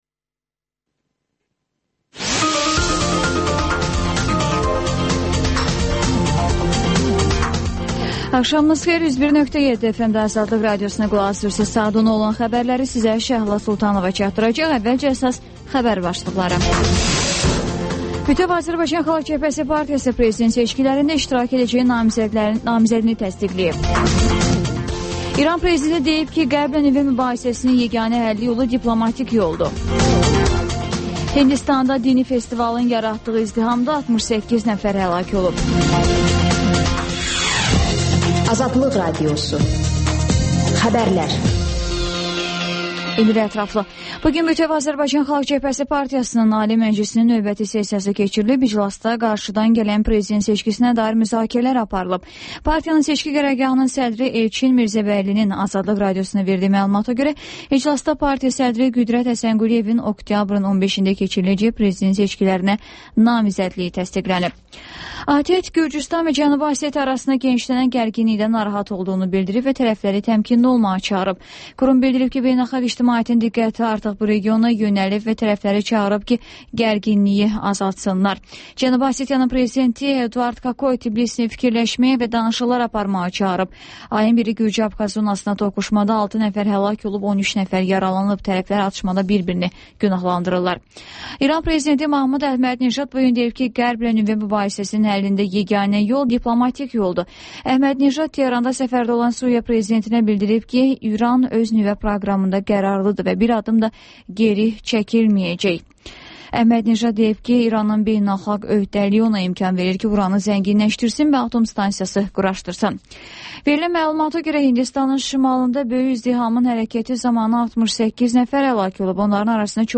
Xəbərlər